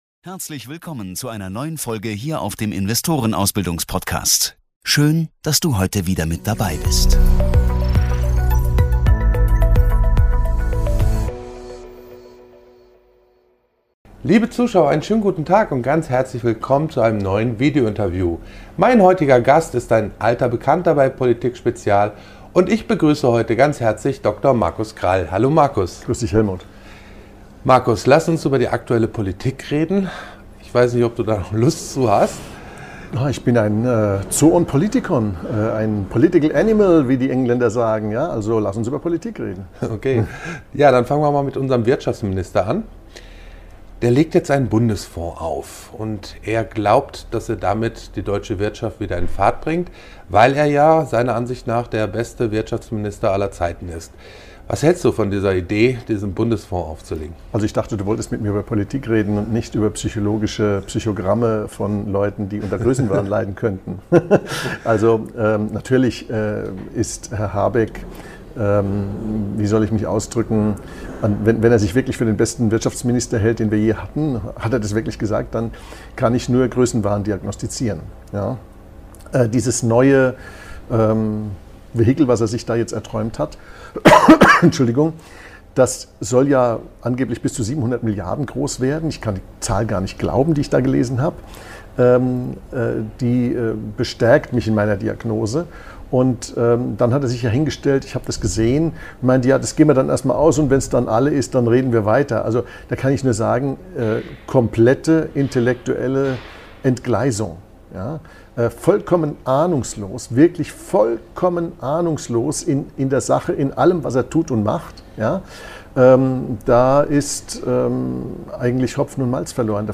Ein aufschlussreiches Interview über notwendige Reformen des Steuer- und Sozialsystems sowie die fundamentale Frage nach den Kernaufgaben des Staates. Das Gespräch wurde im Rahmen unseres Kapitaltags am 25. Oktober 2024 aufgezeichnet.